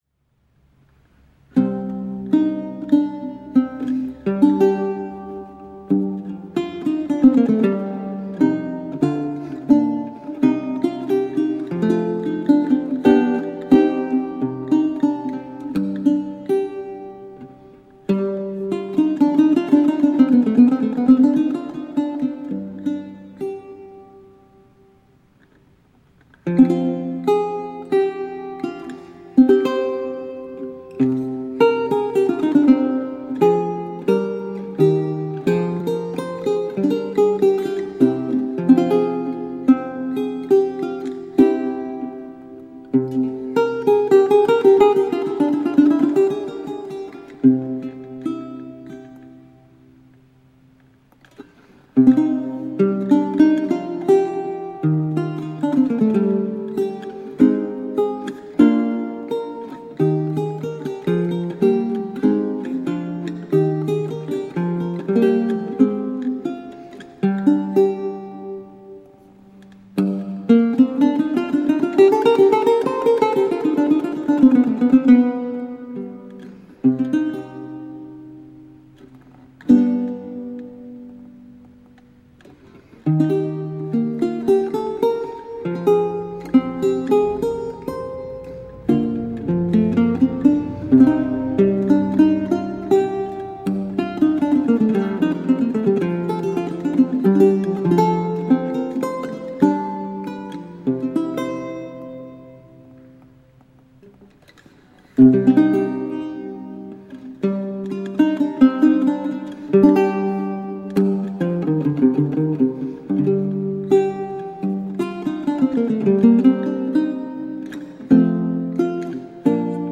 Vihuela, renaissance and baroque lute.
Classical, Renaissance, Instrumental, Lute